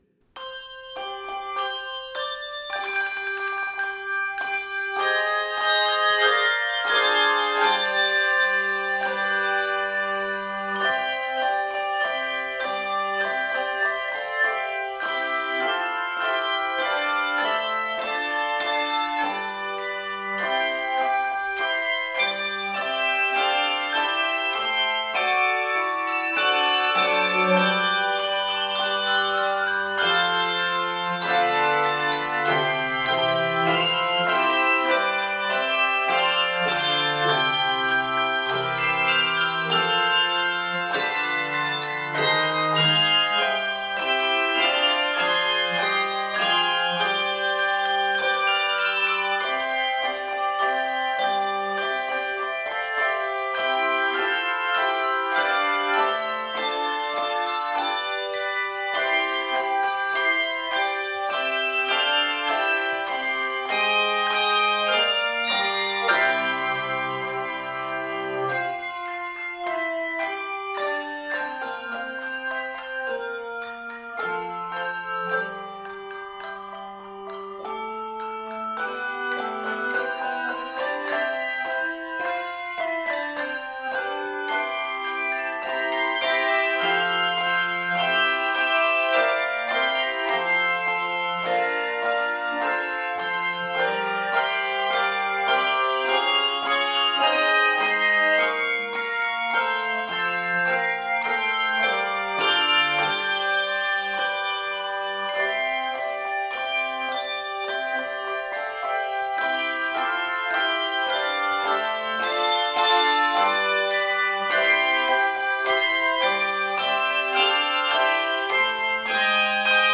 Set of three pieces
is a grand celebration in six-eight time